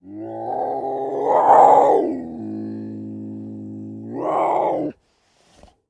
LeopardGrowlHollow AT012603
Leopard Growl. Hollow With Light Breathy Snort. Close Perspective.